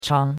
chang1.mp3